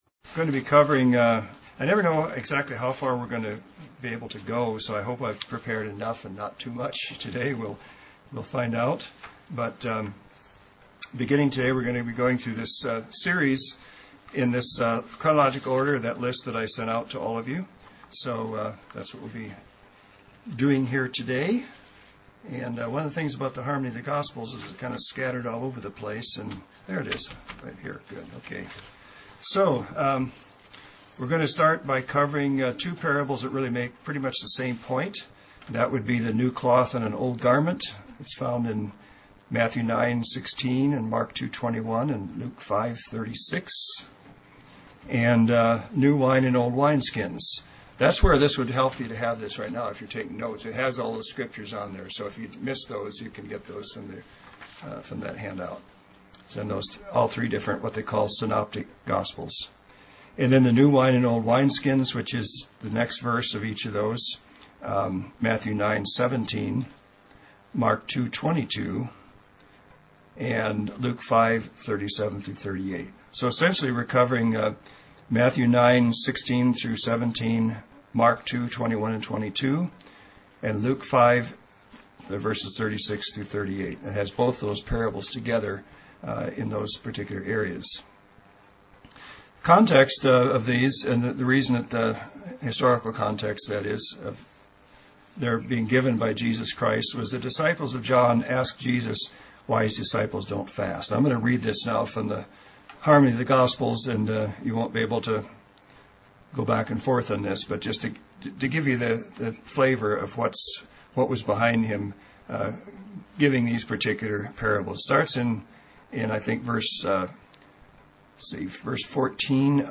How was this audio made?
Given in Central Oregon